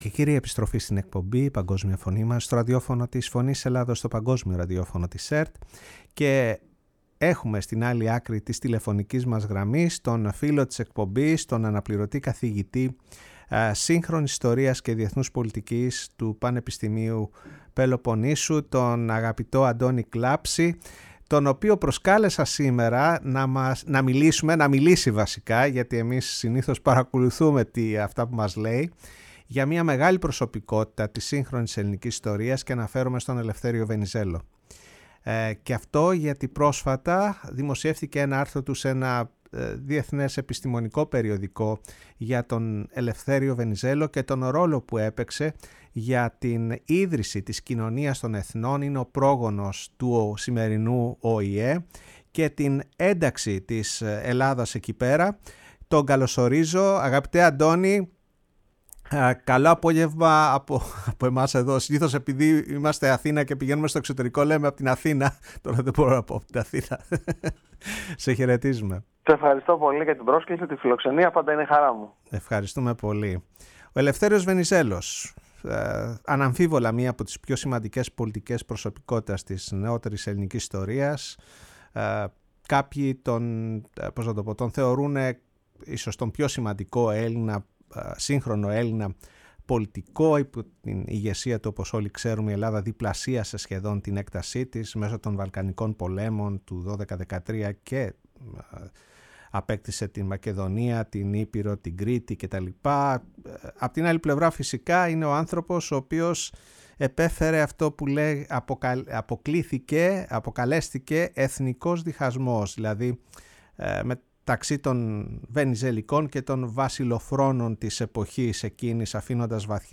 Η ΦΩΝΗ ΤΗΣ ΕΛΛΑΔΑΣ Η Παγκοσμια Φωνη μας ΣΥΝΕΝΤΕΥΞΕΙΣ Συνεντεύξεις 1ΟΣ ΠΑΓΚΟΣΜΙΟΣ ΠΟΛΕΜΟΣ Ελευθεριος Βενιζελος ΚΟΙΝΩΝΙΑ ΤΩΝ ΕΘΝΩΝ